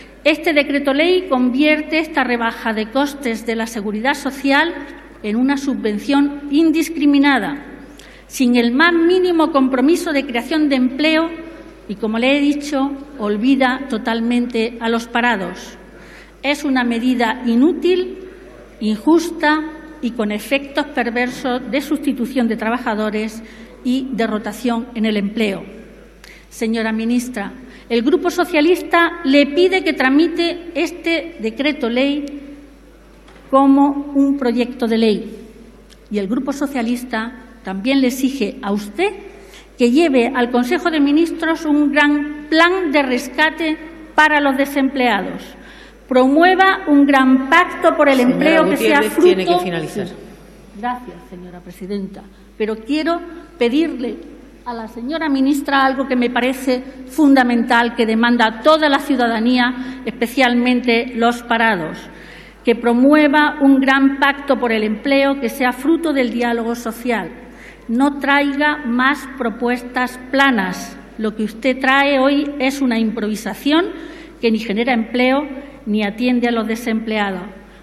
Fragmento de la intervención de Concha Gutiérrez en el pleno durante el debate sobre el decreto ley con el que el gobierno aprueba una tarifa plana para cotizar a la SS por contratos indefinidos